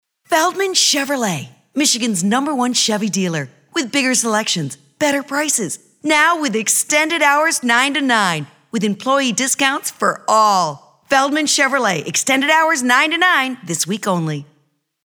PROFESSIONAL, FRIENDLY, CREDIBLE
Sample Voice Over – Feldman Chevrolet